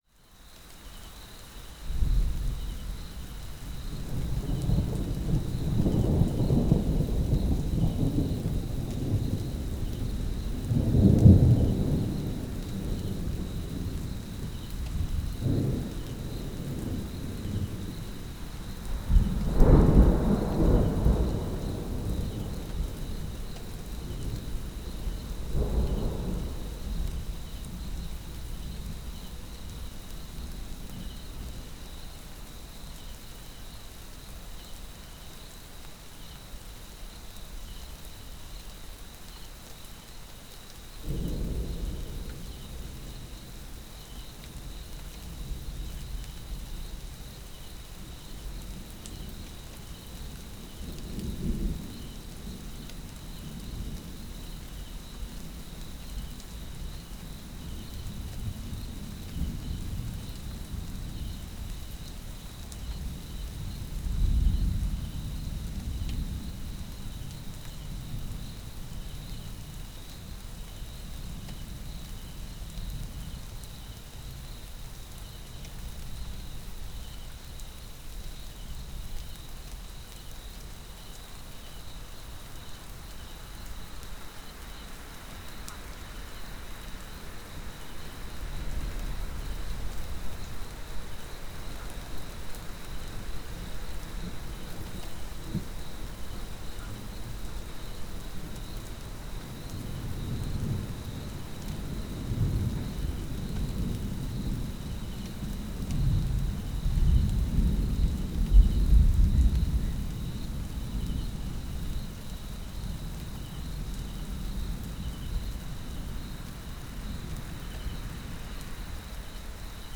copyparty md/au/ambient/Deezer/Deezer - Bruits d'orage anti-stress
06 - Tempête de nuit.flac